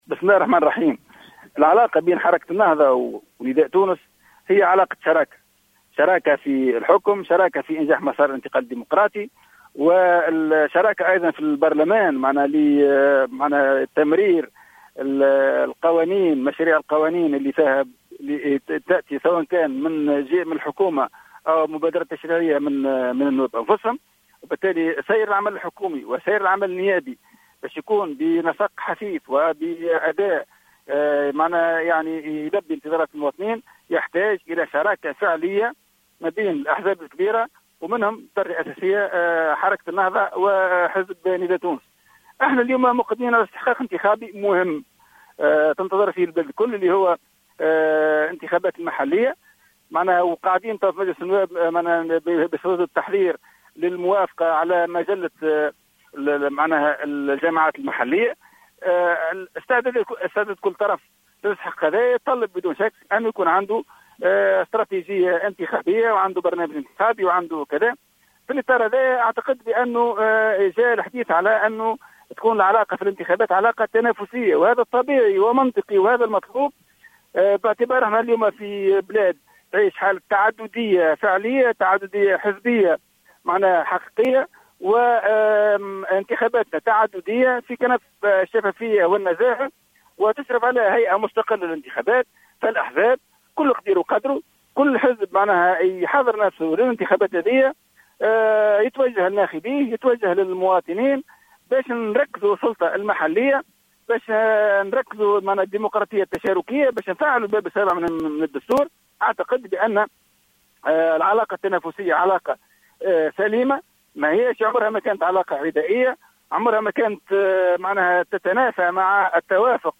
وأكد العجمي الوريمي في تصريح هاتفي للجوهرة أف أم، اليوم الأحد، أن العلاقة التنافسية التي ستكون حاضرة بين الحزبين في الانتخابات البلدية لا تعني العدائية ولا تتنافى مع التوافق والشراكة من أجل إنجاح المسار الديمقراطي، متوقعا أن تقوم العلاقة بين الأحزاب الفائزة بهذا الاستحقاق على التوافق كذلك.